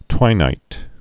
(twīnīt)